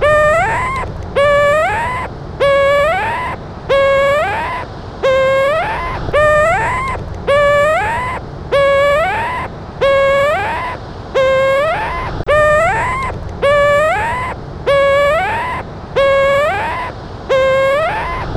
Pelecanoides urinatrix berard - Petrel zambullidor
Pelecanoides urinatrix - Petrel zambullidor.wav